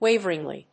音節wá・ver・ing・ly 発音記号・読み方
/‐v(ə)rɪŋ‐(米国英語)/